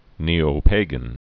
(nēō-pāgən)